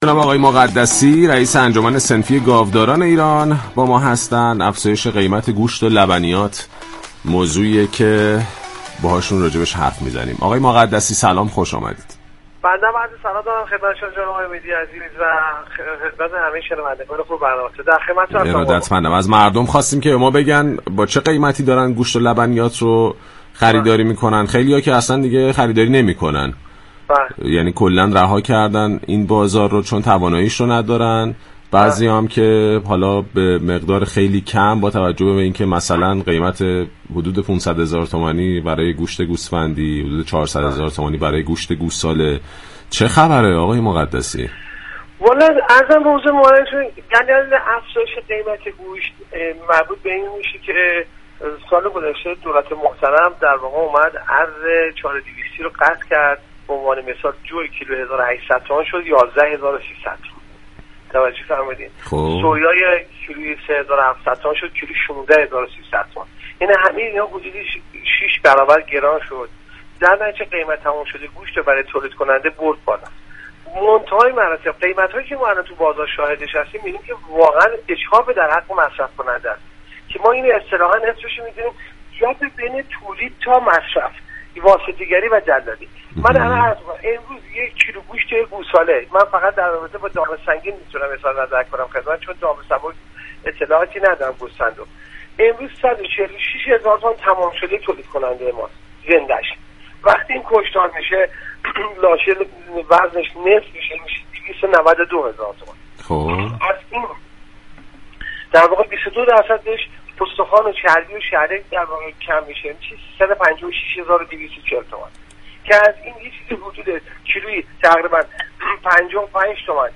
در گفت و گو با «بازار تهران» درخصوص افزایش قیمت گوشت گاو اینگونه اظهار داشت